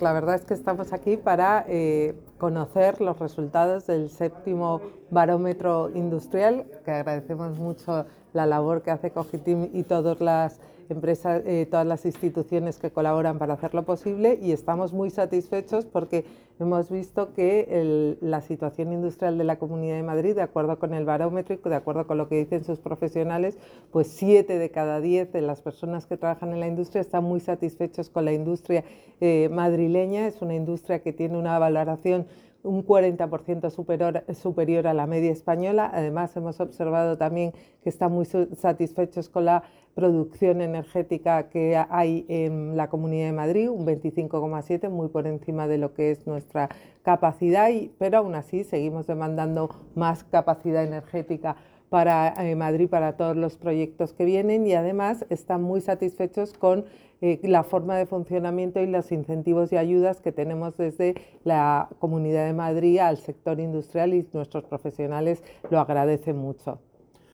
240517_rocio_albert_-_consejera_ehe_-_barometro_industrial.mp3